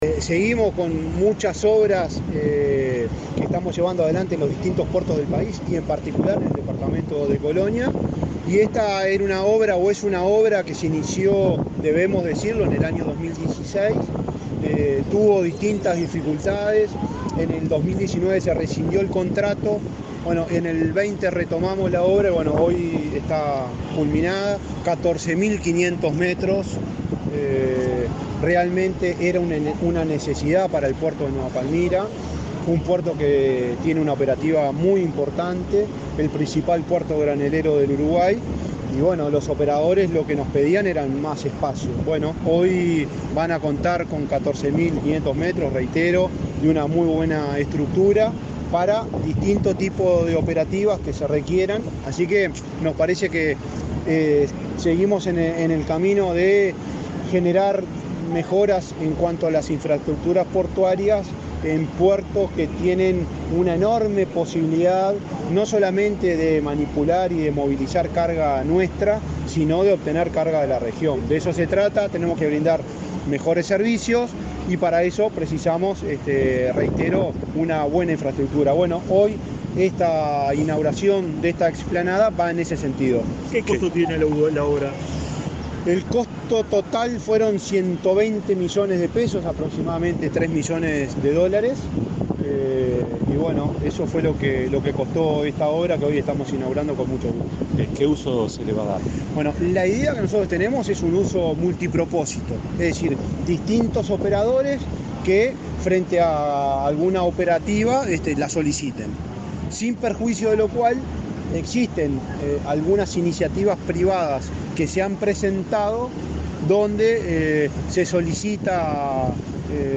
Declaraciones del presidente de la ANP, Juan Curbelo
La Administración Nacional de Puertos (ANP) inauguró este miércoles 19 la explanada sur del puerto de Nueva Palmira, en Colonia.